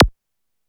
ZAP01.WAV